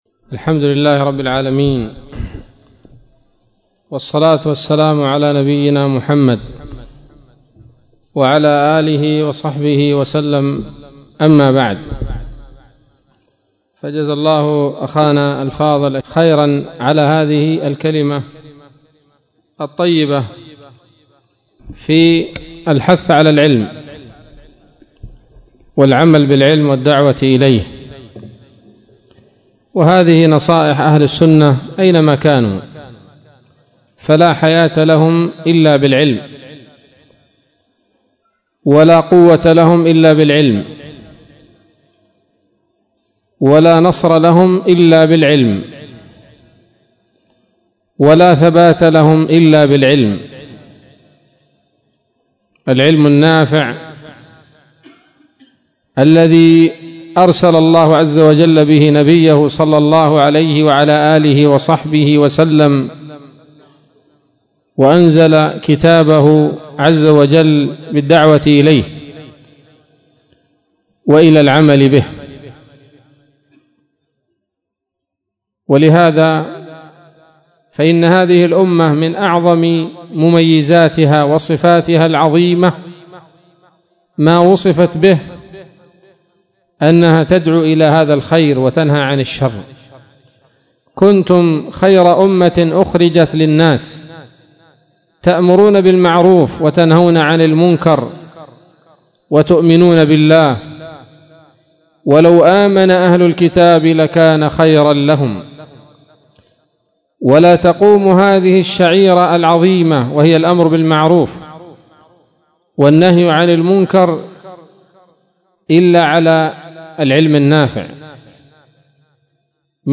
كلمة قيمة بعنوان: ((العلم والجهاد)) ليلة الخميس 25 ربيع أول 1439هـ بدار الحديث السلفية بصلاح الدين